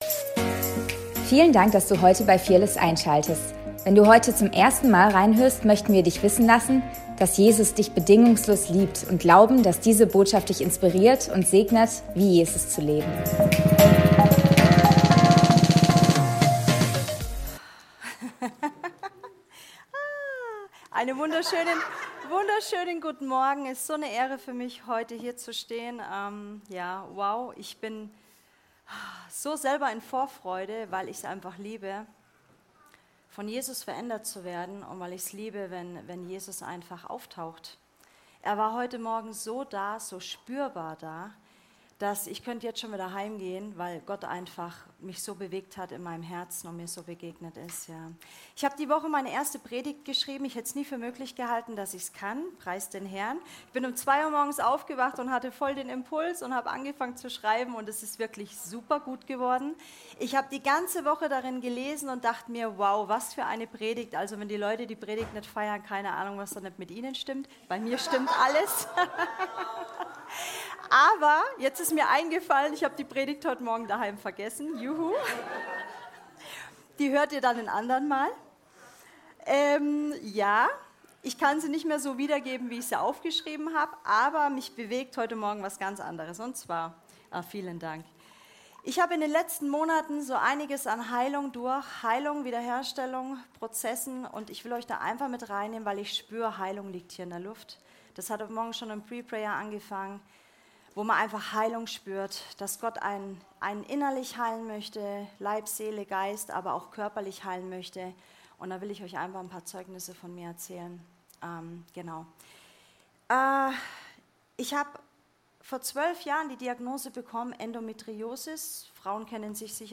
Predigt vom 25.08.2024